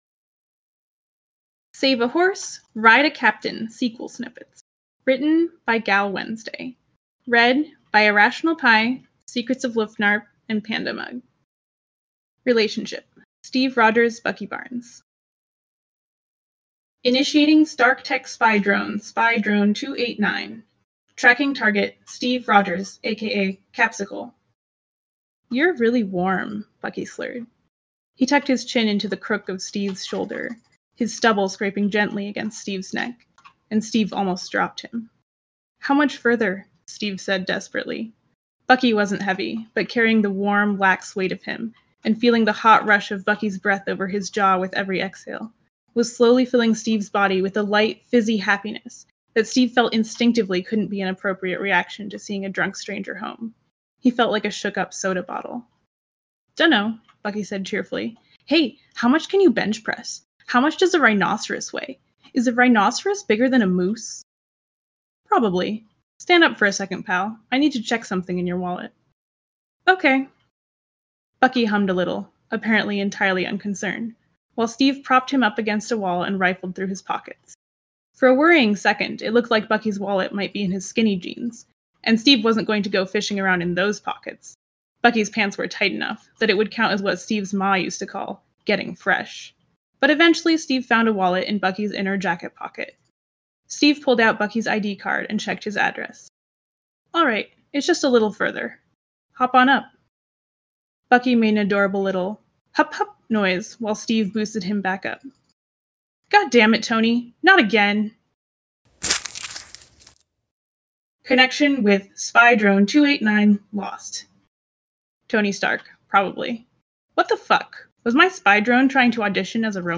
format|podvid, collaboration|ensemble